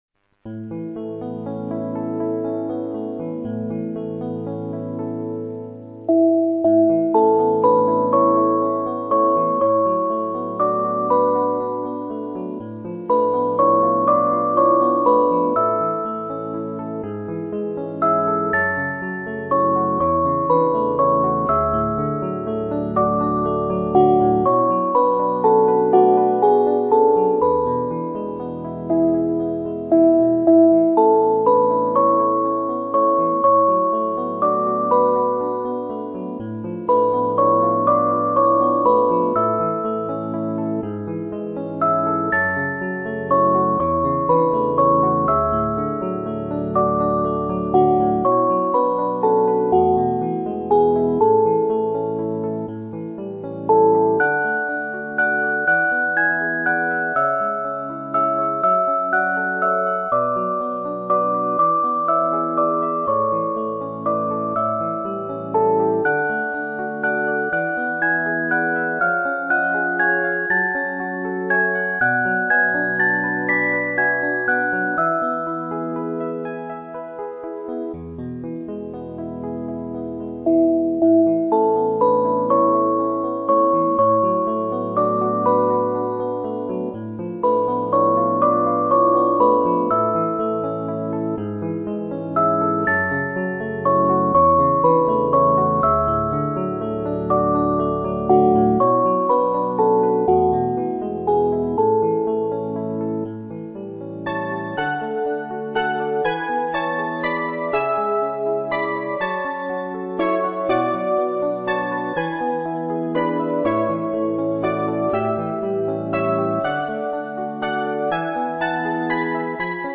アンサンブル曲